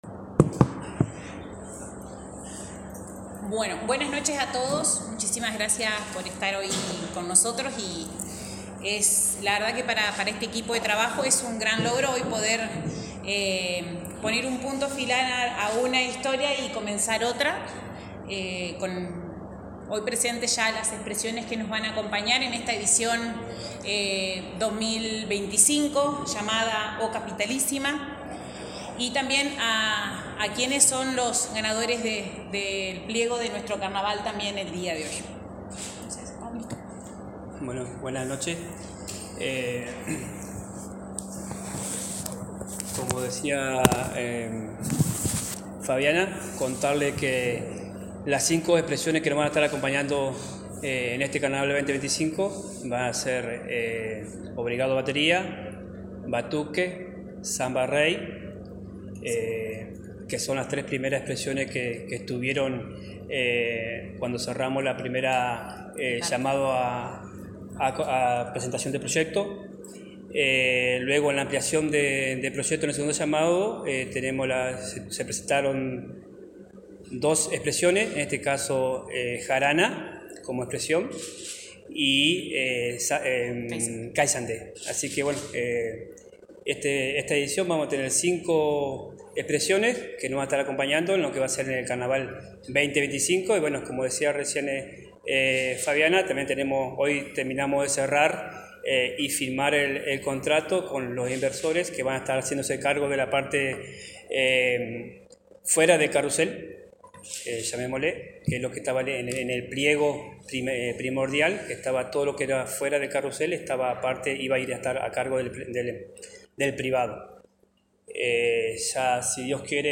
01-conferncia.mp3